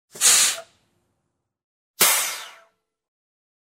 Звуки воздуха
Пневматическая тормозная система грузового автомобиля